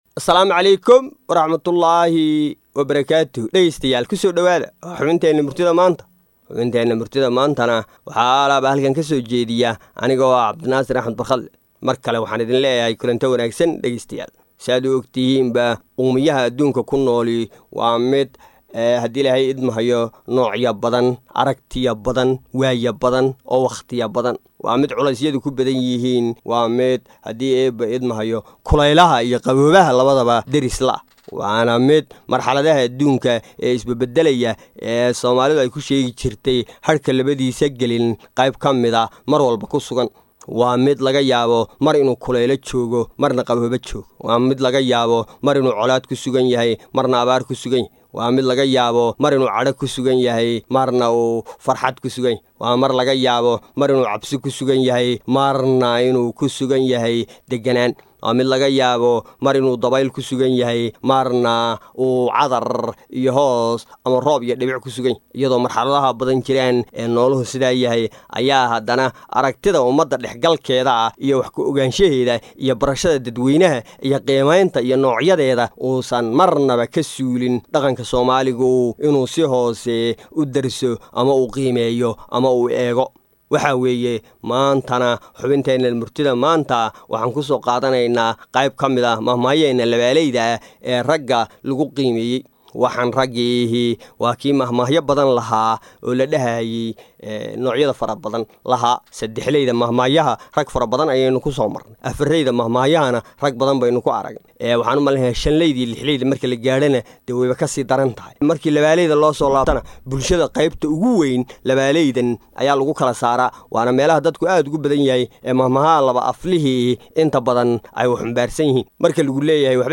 Soo jeedinta abwaan